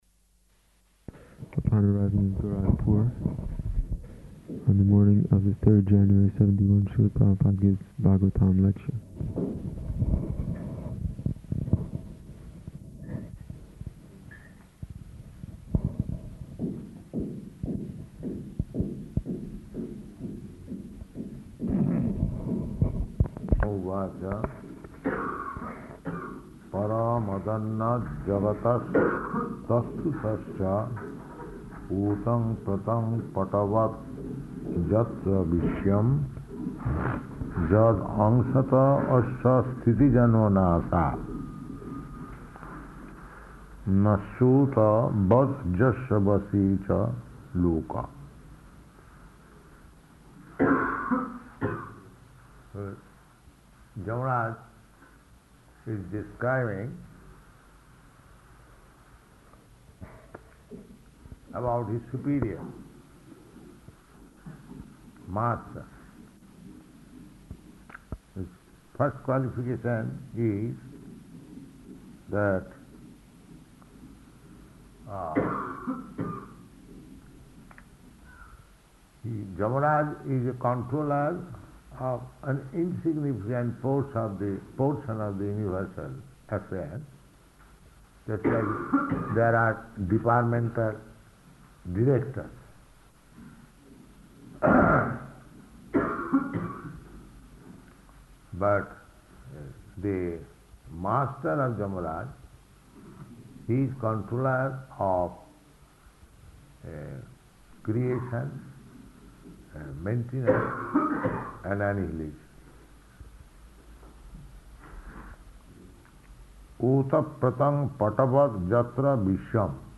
Śrīmad-Bhāgavatam 6.3.12 --:-- --:-- Type: Srimad-Bhagavatam Dated: February 3rd 1971 Location: Gorakphur Audio file: 710203SB-GORAKPHUR.mp3 Devotee: [introducing recording] On arrival in Gorakhpur, on the morning of the 3rd January [sic] '71, Śrīla Prabhupāda gives Bhāgavatam lecture.